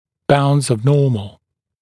[baundz əv ‘nɔːml][баундз ов ‘но:мл]границы нормы